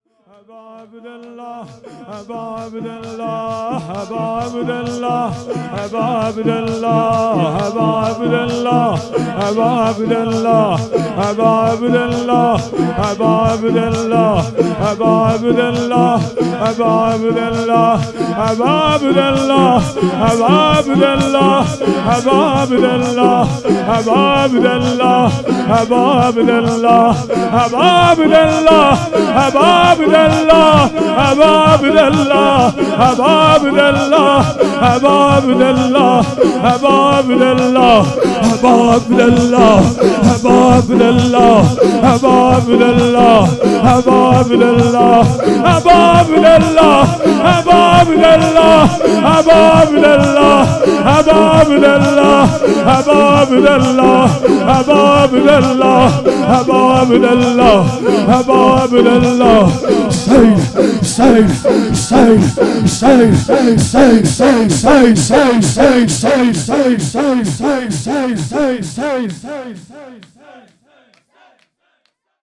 هیئت حسن جان(ع) اهواز - شور ، ذکر
دهه اول محرم الحرام ۱۴۴۴